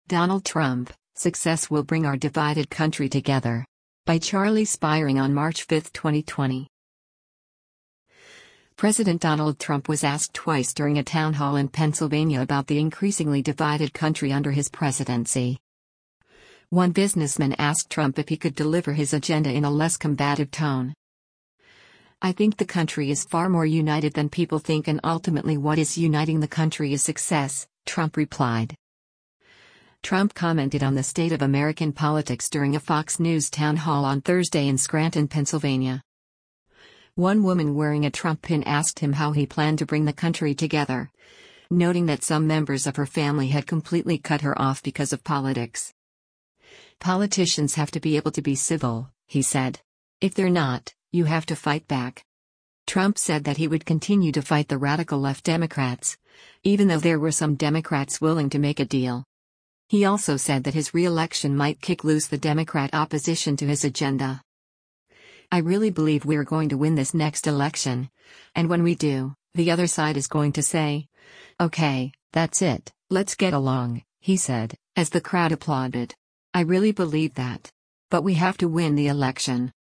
President Donald Trump was asked twice during a town hall in Pennsylvania about the increasingly divided country under his presidency.
One businessman asked Trump if he could deliver his agenda in a less combative tone.
Trump commented on the state of American politics during a Fox News town hall on Thursday in Scranton, Pennsylvania.